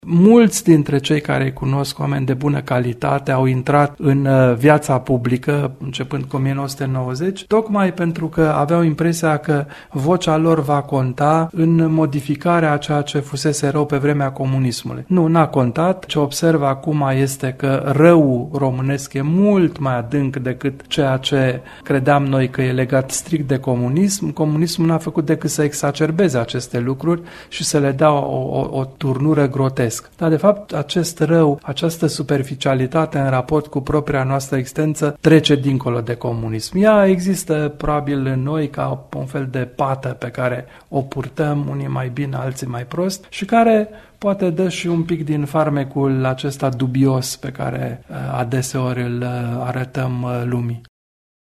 Ascultaţi o secvenţă din emisunea difuzată în original vineri, 20 iunie, de la ora 19.10, pe 630 kHz – unde medii: